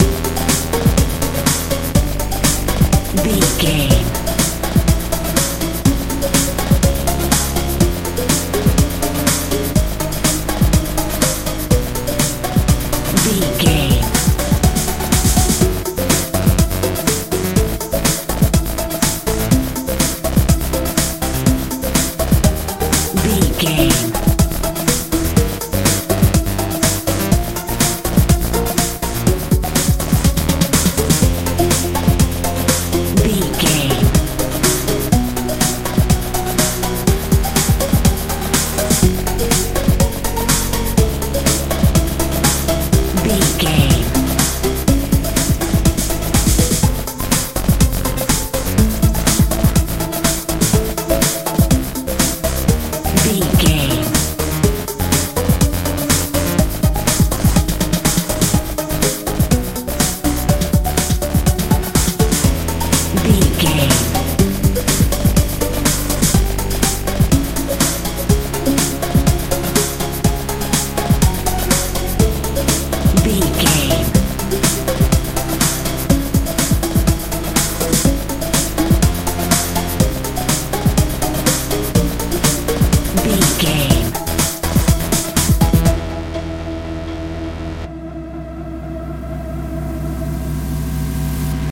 modern dance feel
Aeolian/Minor
C♯
Fast
motivational
energetic
synthesiser
bass guitar
drums
80s
suspense